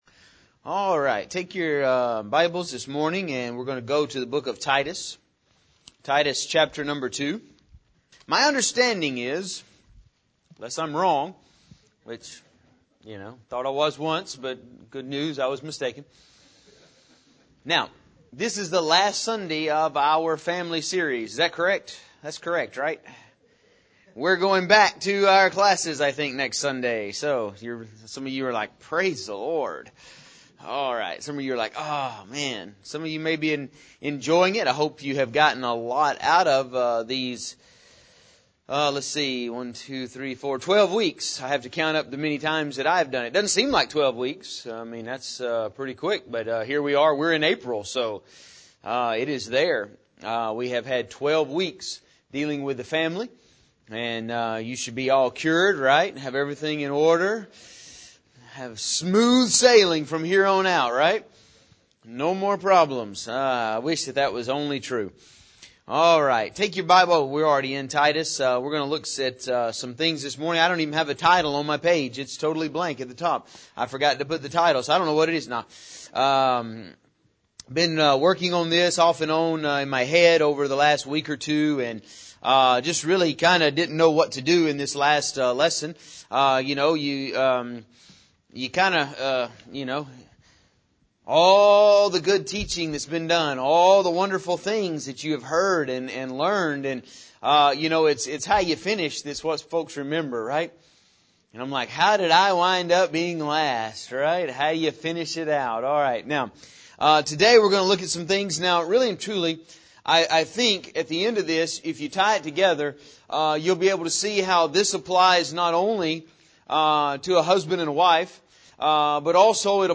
In this lesson we will show some types of the wrong kind of love for children and what our goal should be when it comes to loving our children.